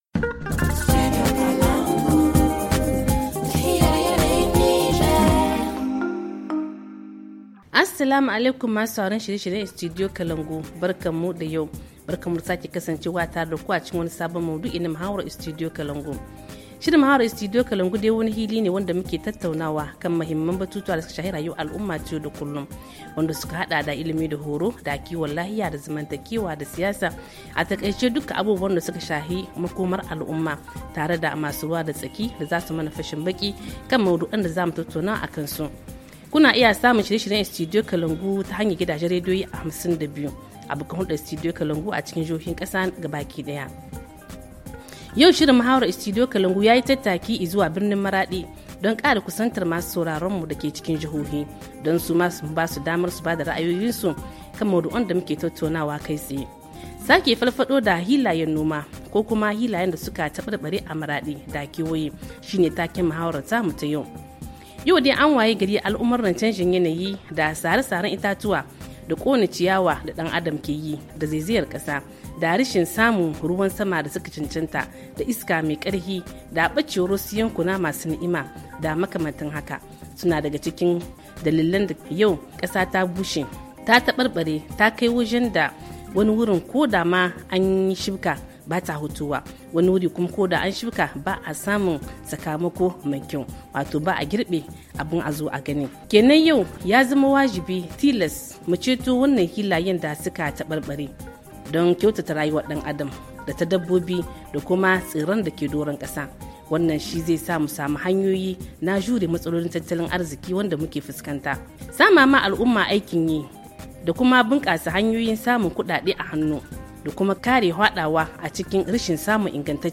Le forum en haoussa